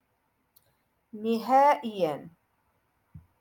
Moroccan Dialect- Rotation Six - Lesson Two Five